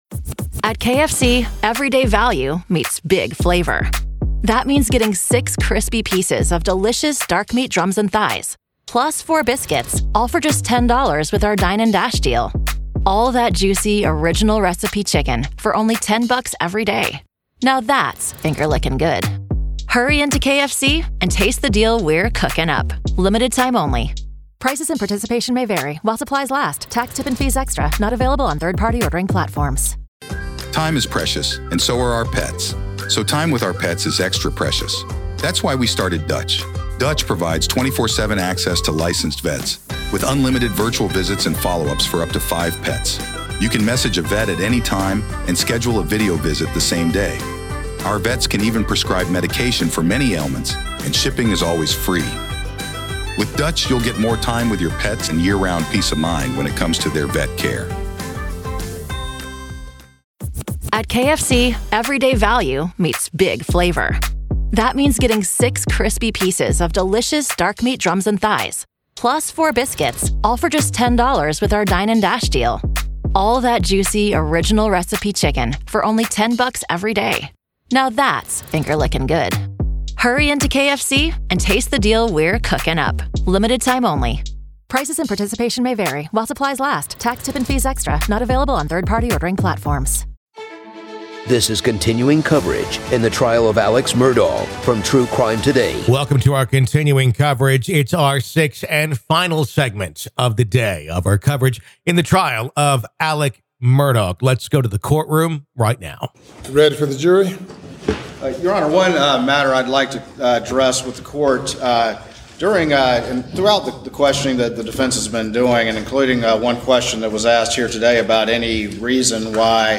The Trial Of Alex Murdaugh | FULL TRIAL COVERAGE Day 6 - Part 6